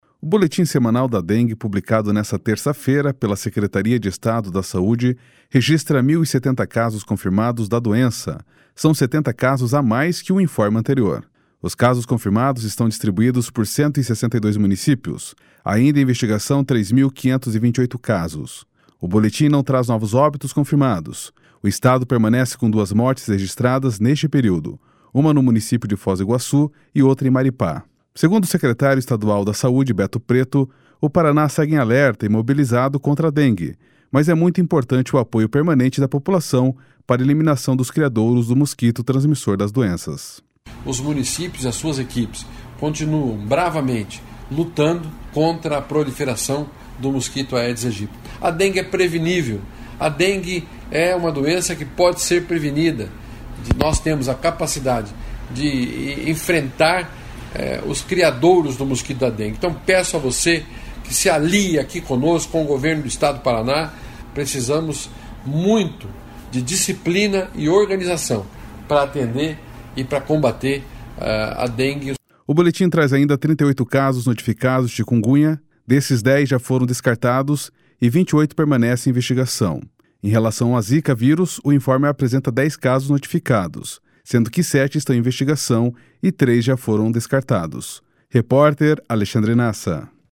Segundo o secretário estadual da Saúde, Beto Preto, o Paraná segue em alerta e mobilizado contra a dengue, mas é muito importante o apoio permanente da população para a eliminação dos criadouros do mosquito transmissor das doenças.//SONORA BETO PRETO//